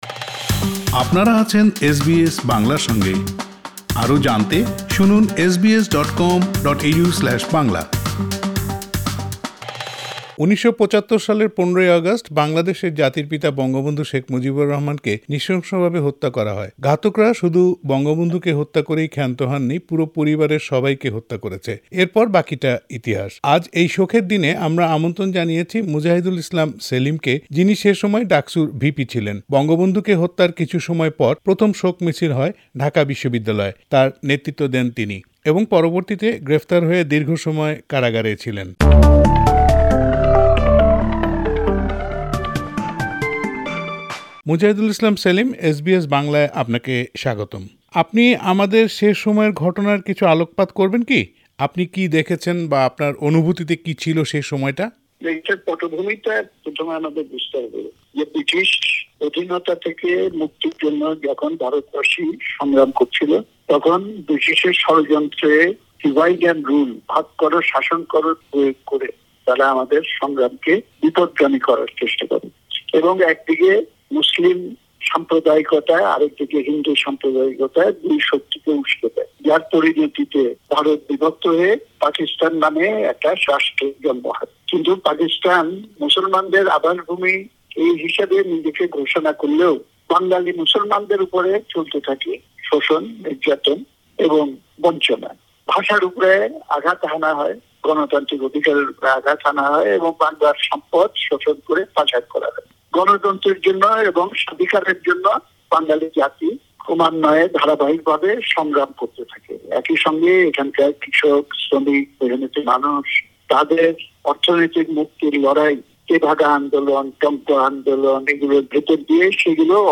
সেই সময়কার ঘটনা প্রবাহের কিছু বর্ণনা তিনি তুলে ধরেছেন তার সাক্ষাৎকারে।